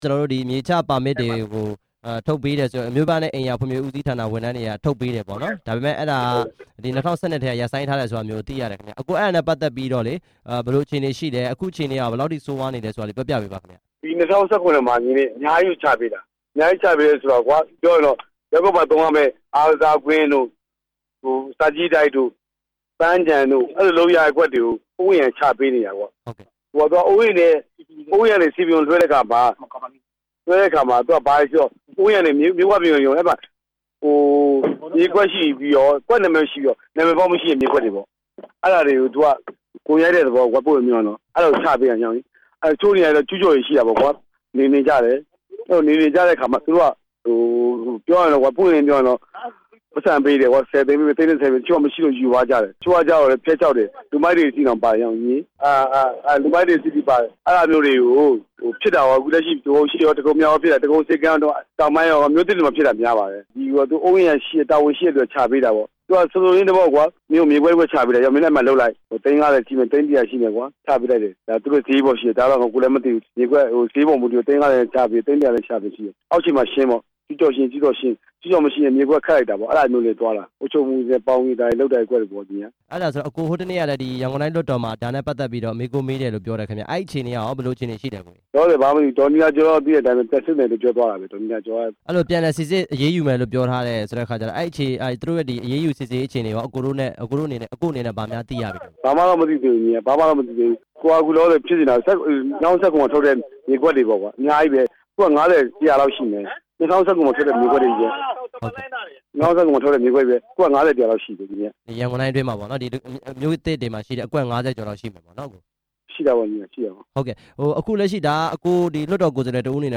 တရားမဝင် မြေချ ပါမစ်တွေအကြောင်း ဆက်သွယ်မေးမြန်းချက်